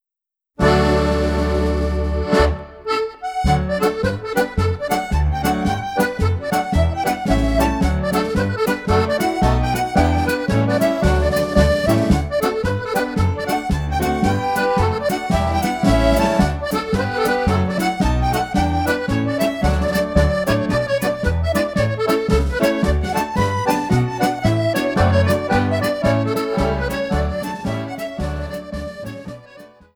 Jig